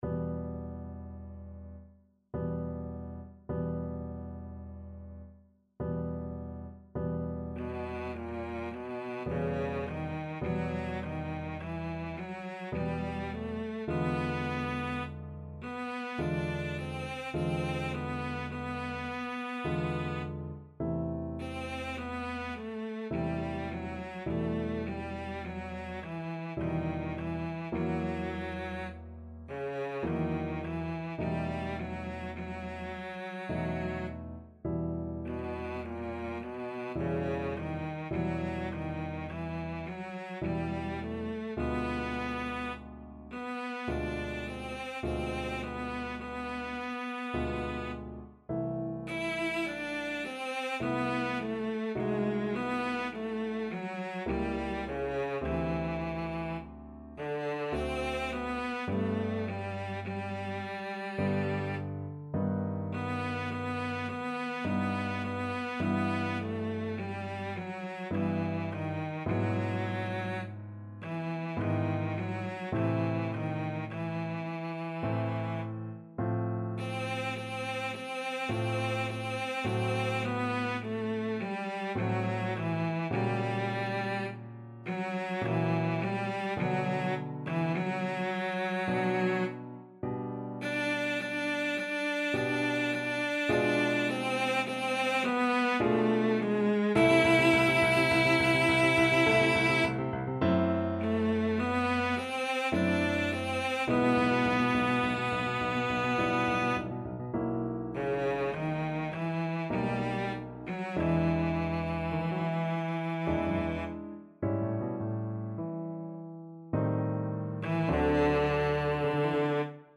Cello Classical
Key: E minor (Sounding Pitch)
Time Signature: 3/4
Tempo Marking: Andante molto moderato